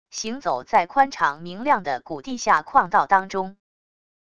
行走在宽敞明亮的古地下矿道当中wav音频